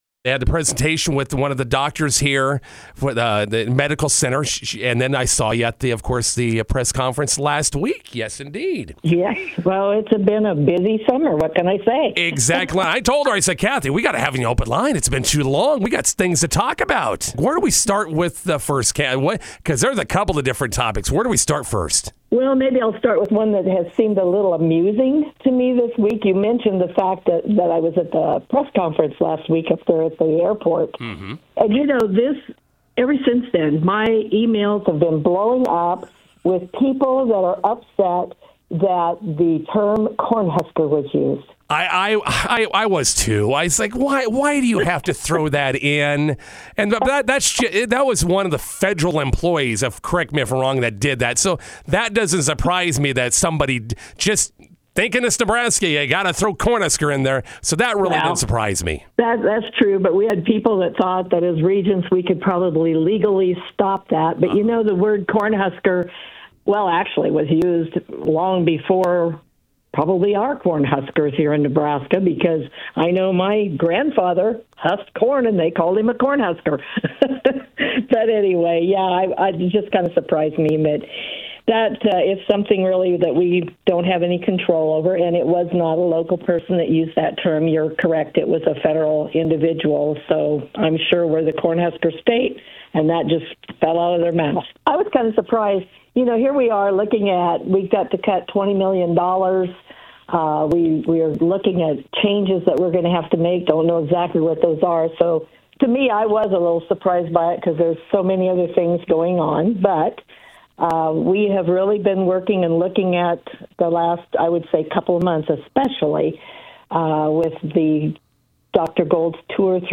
INTERVIEW: University of Nebraska Board of Regent Kathy Wilmot with the latest news from the University of Nebraska school campuses.